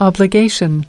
29. obligation (n) /ˌɒblɪˈɡeɪʃn/: nghĩa vụ, bổn phận